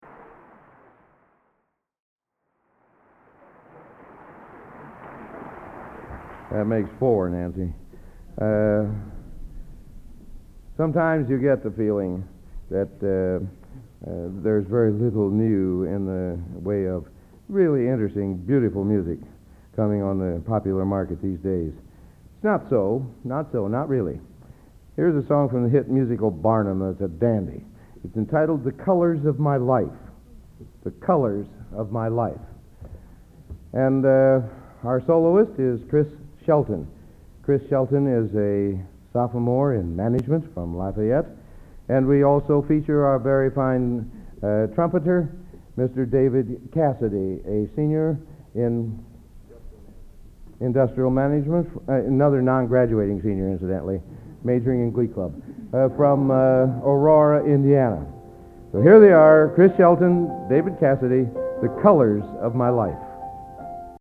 Collection: End of Season, 1982
Location: West Lafayette, Indiana
Genre: | Type: Director intros, emceeing